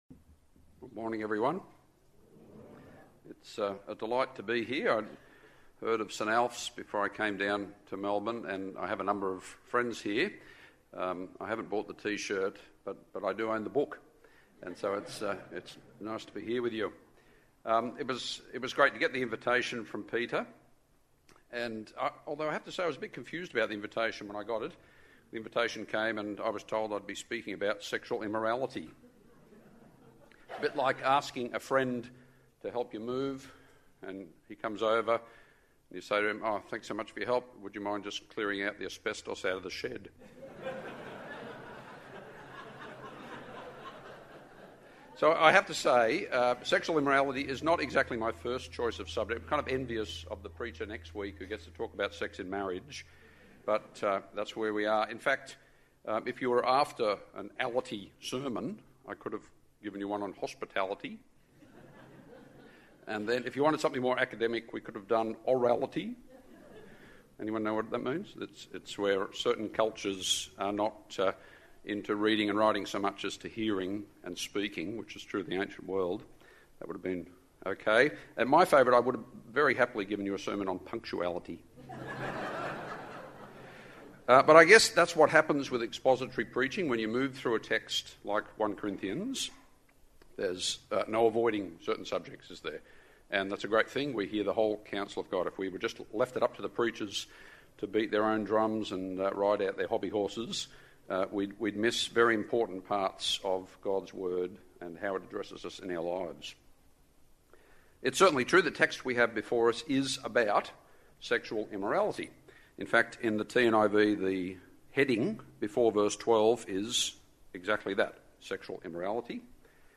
Includes Q&A. In this sermon